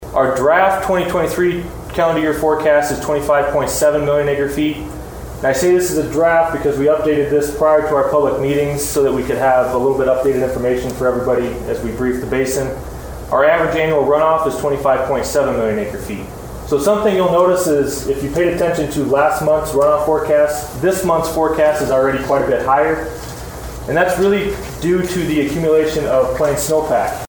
at a meeting in Bismarck